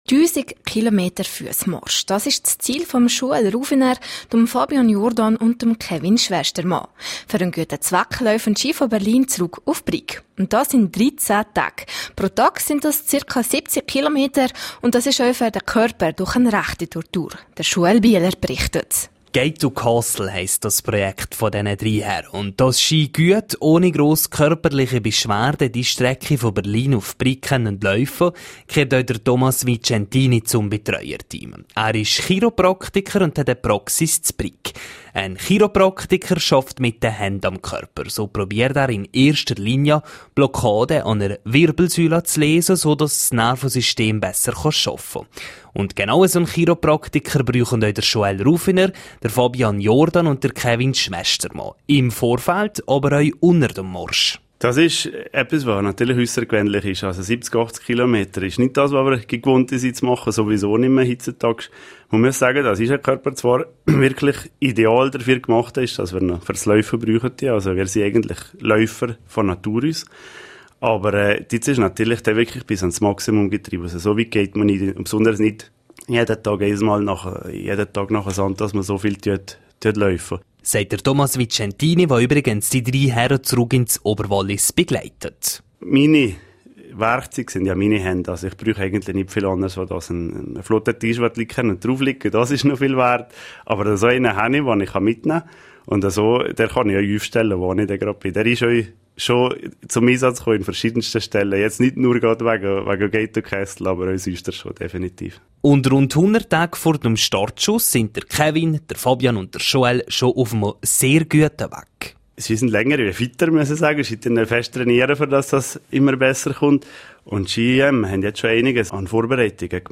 Gate2Castle auf RRO Interview Newsletter abonnieren Die Praxis für Chiropraktik und Physiotherapie Vicentini wechselt den Namen auf Vitalistica und erweitert ihr Angebot.